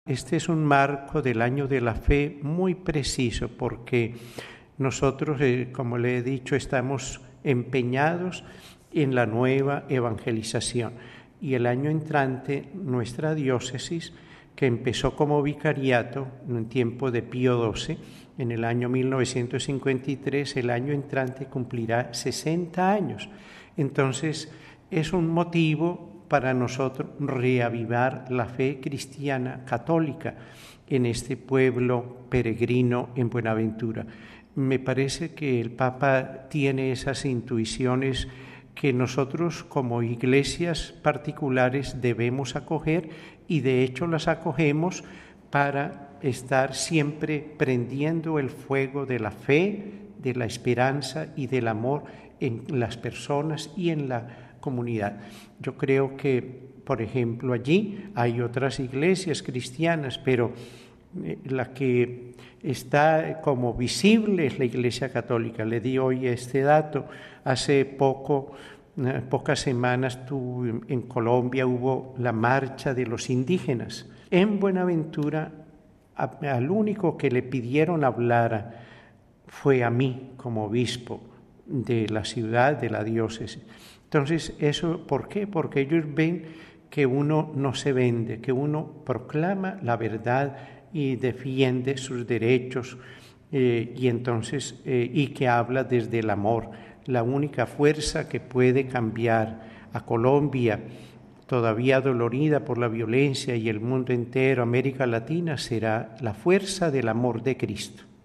Mons. Epalza destaca que esta visita ad Limina se viene desarrollando en el marco del Año de la Fe convocado por el Papa (Audio): RealAudio